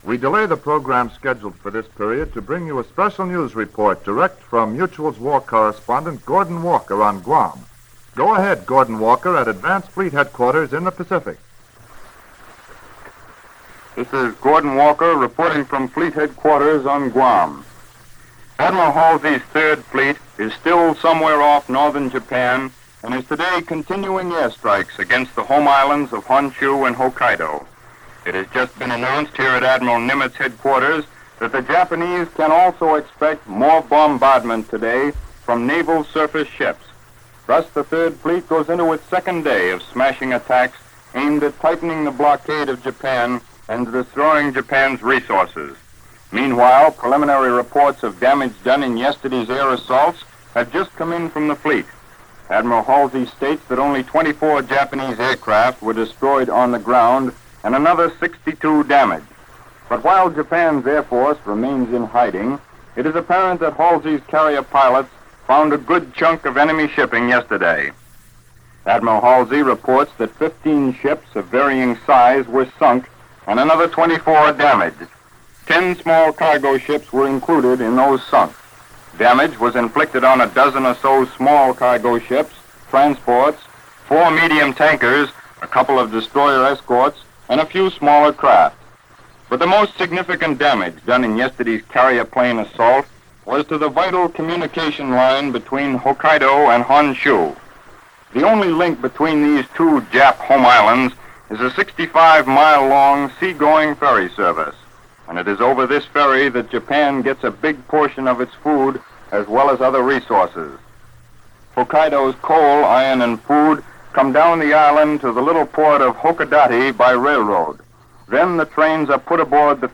July 14, 1945 - Cutting the Line between Hokkaido And Honshu - The 3rd Fleet Off Japan - The Bombing Starts - News For This day From Mutual.